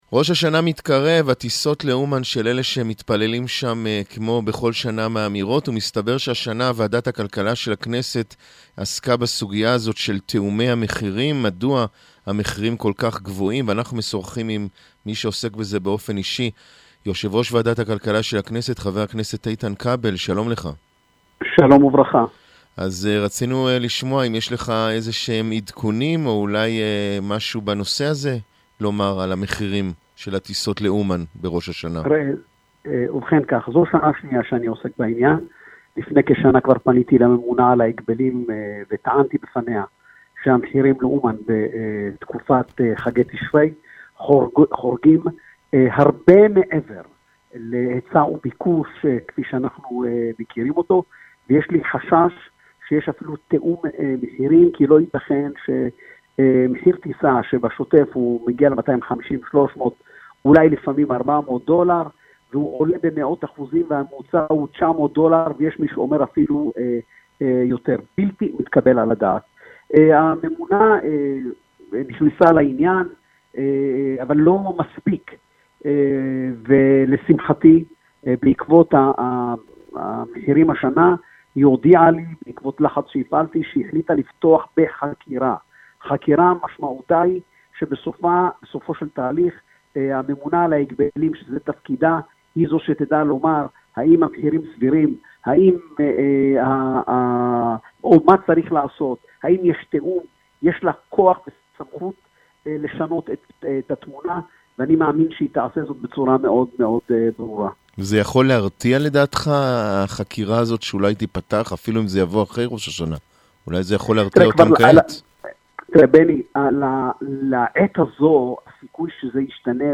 Председатель Экономического комитета – 7 каналу.